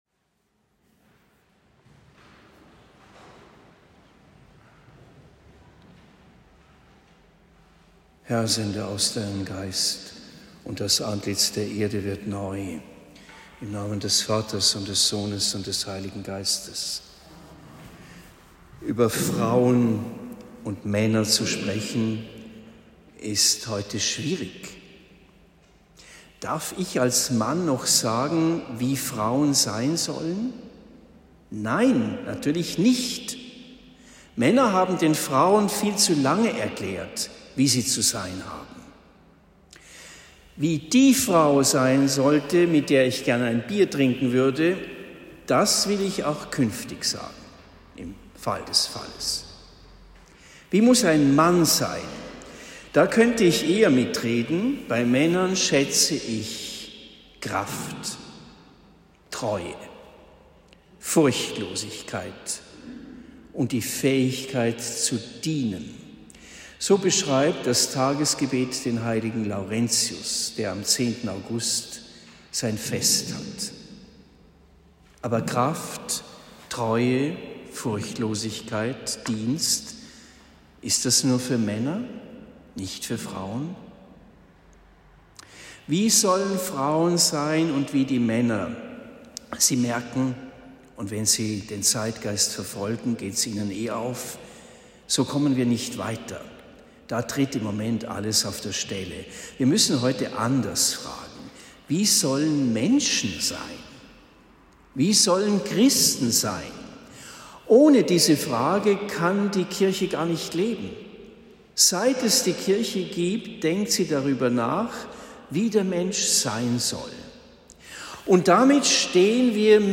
Predigt am 18. August 2023 in Marktheidenfeld St. Laurentius (Laurenzi-Festwoche)